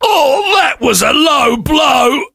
sam_die_vo_03.ogg